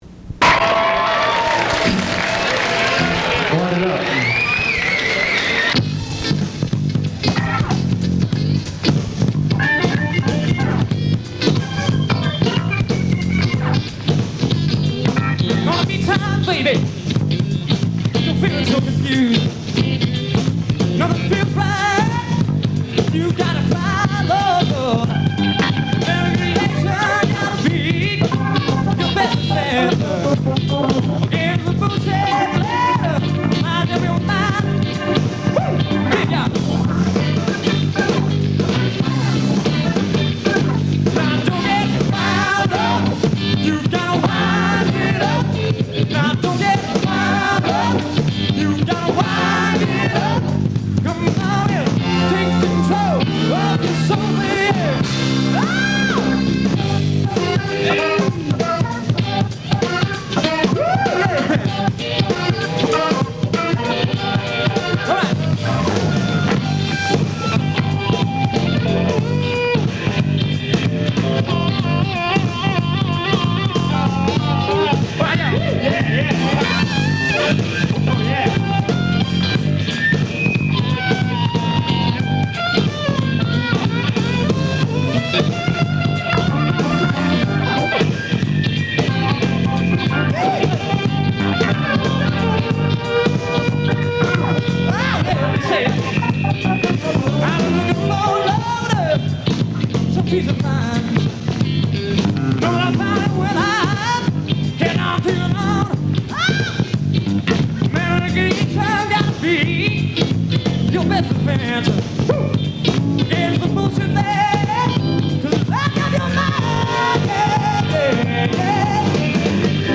Pop group, formed in 1985 in Toronto, Ont.
Source : Radio -> Cassette -> WAV -> MP3 Author